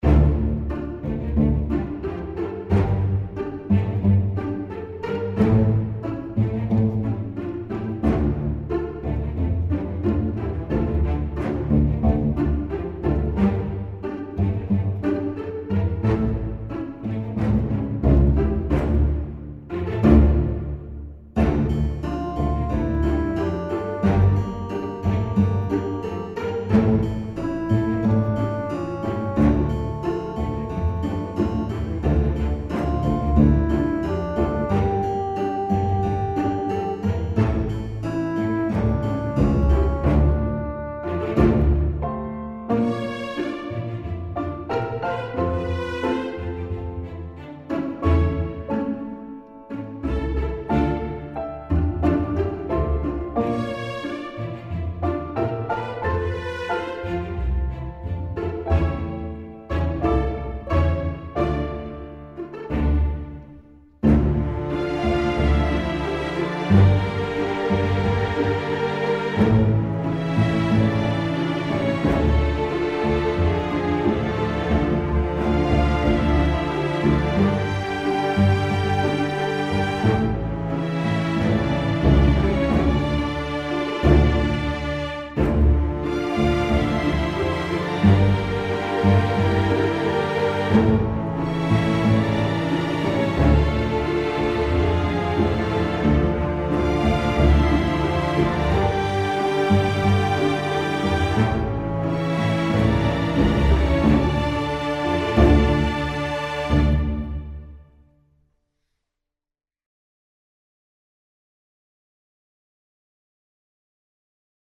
violons - epique - profondeurs - aerien - melodieux